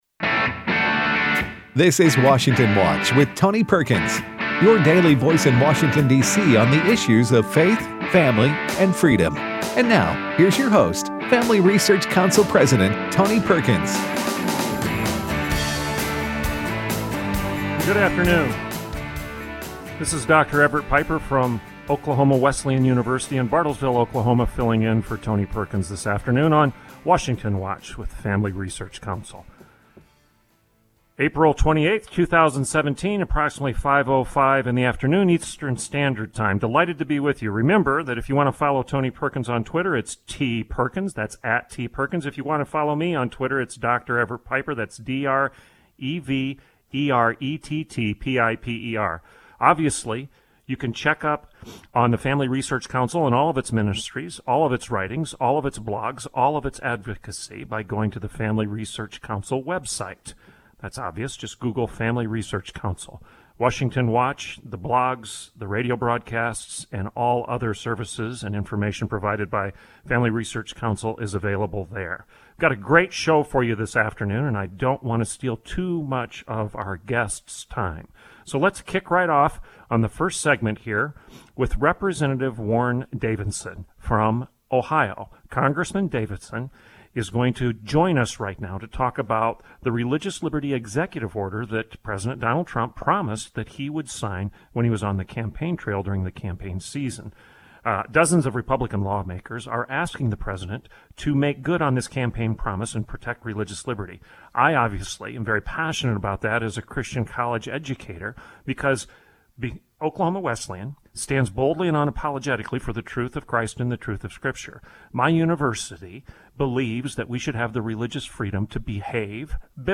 In the second segment we have Missouri AG Josh Hawley to discuss crackdown on human trafficking.